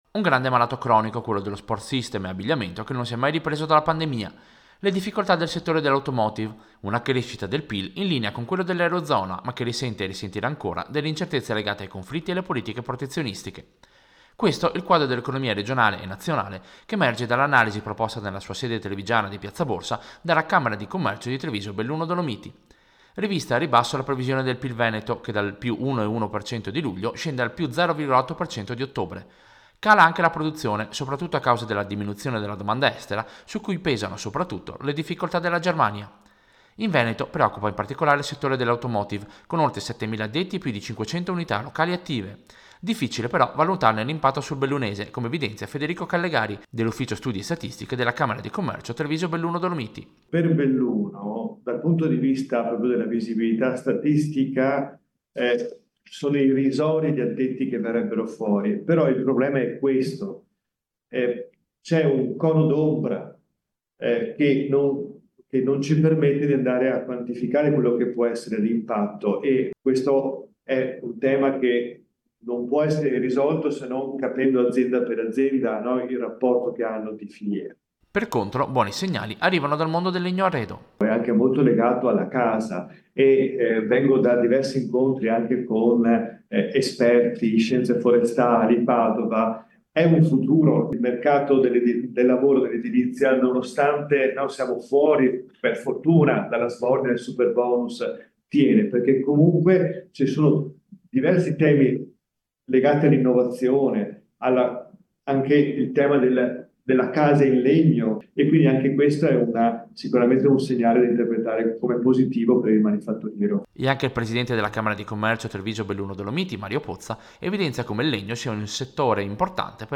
Servizio-Dati-CCIAA-Novembre-2024.mp3